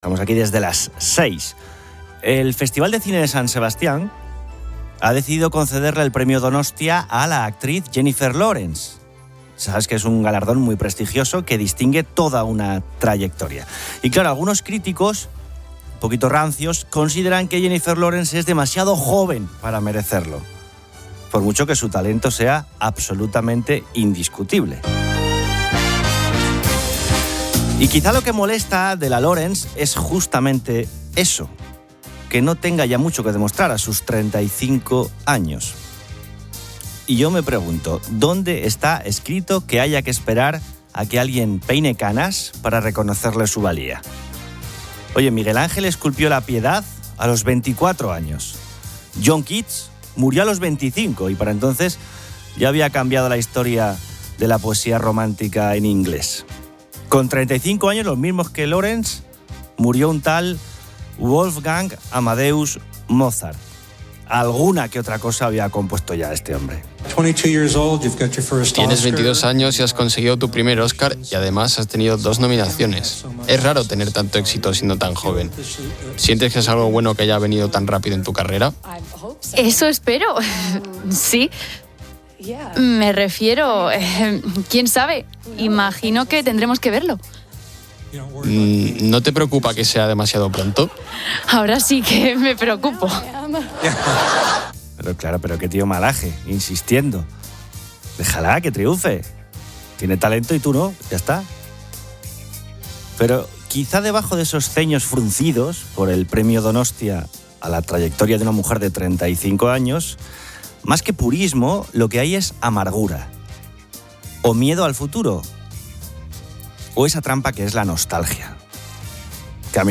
Posteriormente, el locutor critica la visión anticuada sobre el reconocimiento de la juventud, ejemplificado con Jennifer Lawrence y el Premio Donostia. Aborda la falta de apoyo a la juventud en España (formación, vivienda, empleo) y los problemas del sistema de pensiones, comparando la situación con propuestas de otros países. También se discuten las causas de los incendios forestales, incluyendo la falta de apoyo al sector primario y el despoblamiento rural.